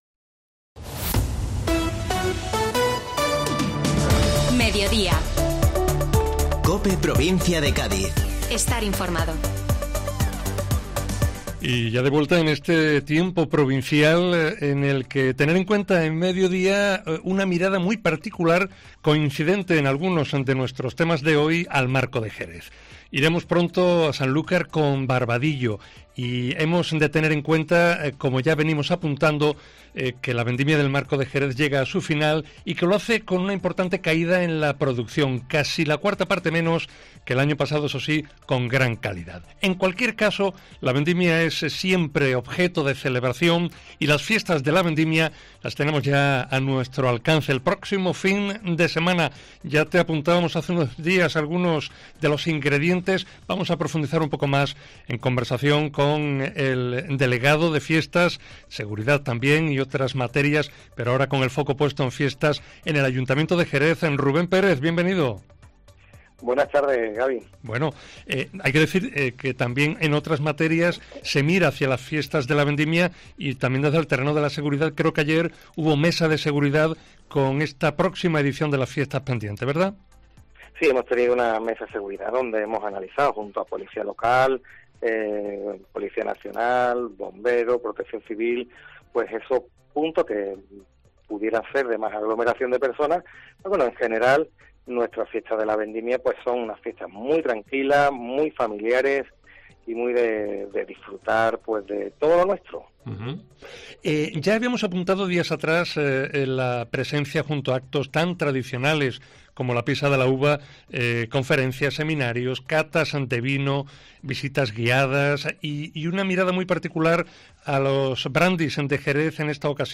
Rubén Pérez, delegado de Seguridad y Fiestas del Ayuntamiento, detalla en Mediodía COPE la variada programación de las Fiestas de la Vendimia a celebrar del 3 al 18 de septiembre
Escucha aquí a Rubén Pérez, delegado de Seguridad y Fiestas del Ayuntamiento de Jerez